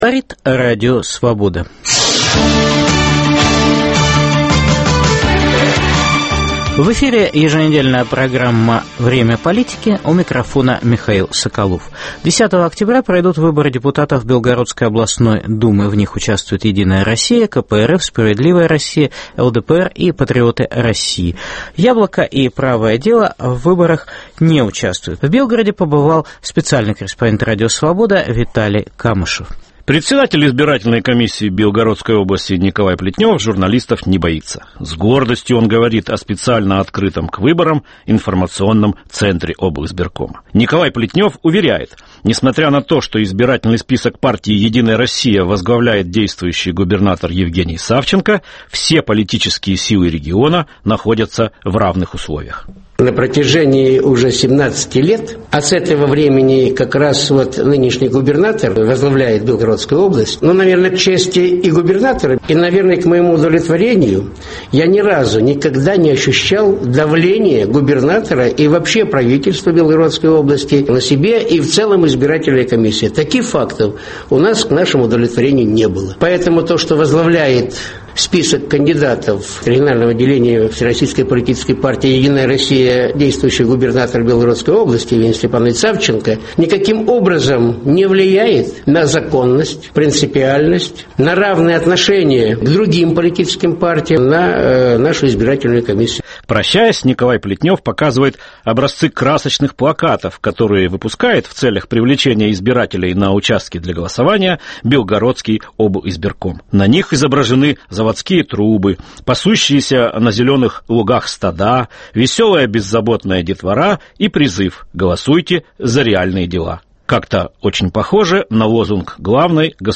Тихий омут Белгородской политики, - специальный репортаж
специальный репортаж из Костромы